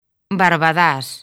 Transcripción fonética
baɾβaˈðas